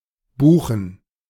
Buchen (German pronunciation: [ˈbuːxn̩]